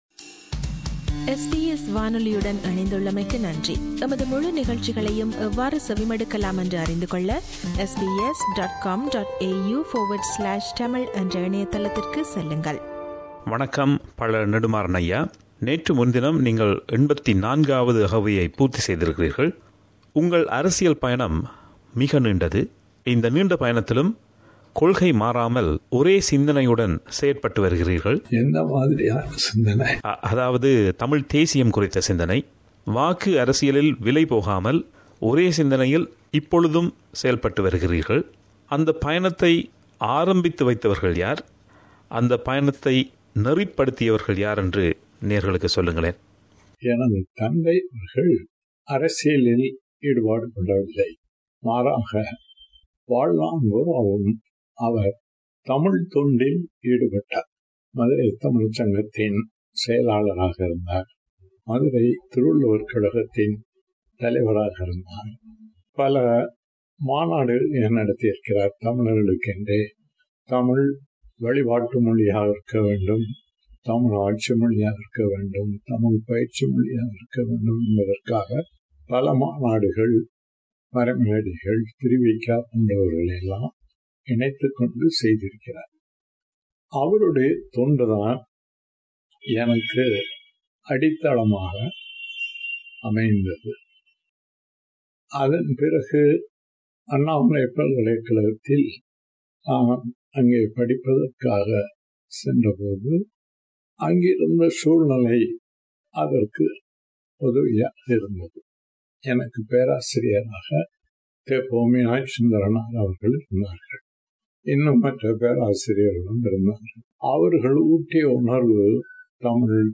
பழ. நெடுமாறன் அவர்களுடனான நேர்காணலின் முதல் பாகம்.